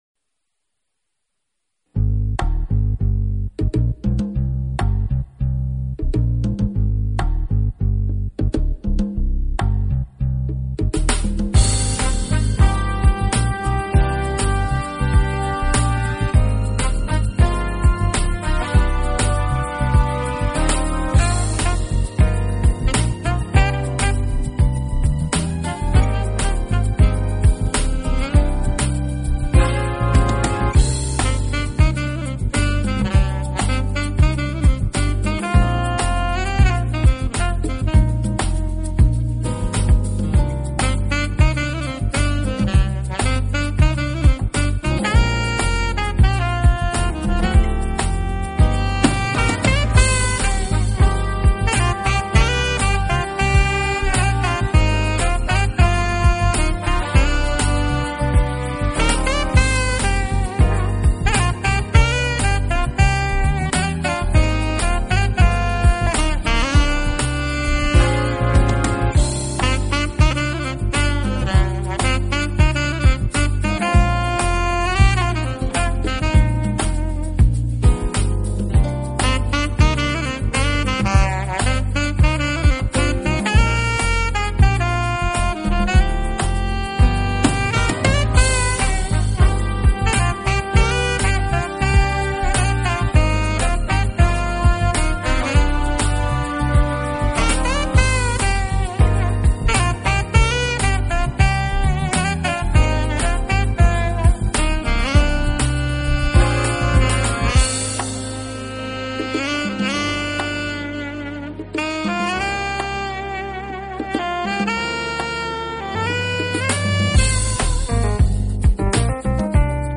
Genre: Smooth Jazz/Jazz